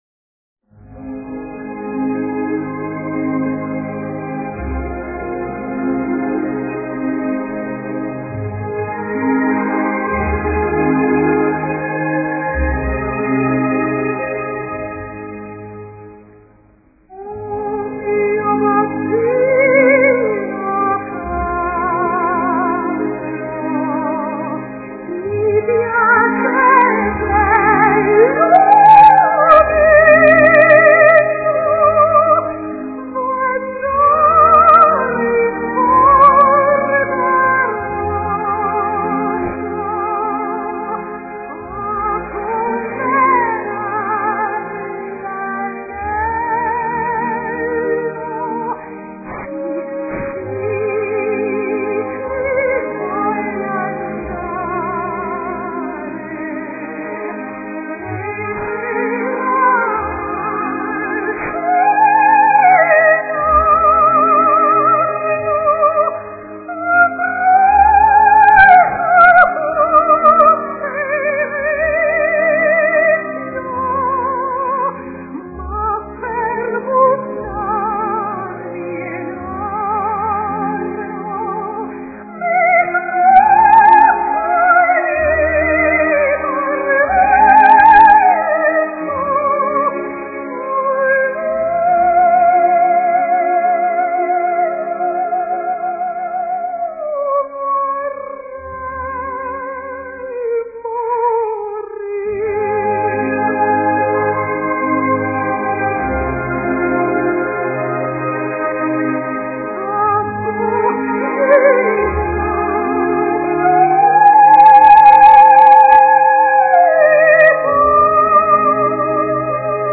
no final tem um Link para Abrir a Música que é Cantada.
Opera